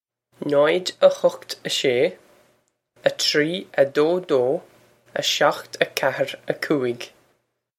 Pronunciation for how to say
naw-ij, a hukht, a shay, a tree, a doh, doh, a shokht, a kya-hir, a coo-ig
This is an approximate phonetic pronunciation of the phrase.